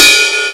Index of /m8-backup/M8/Samples/Fairlight CMI/IIX/CYMBALS
CYMBELL1.WAV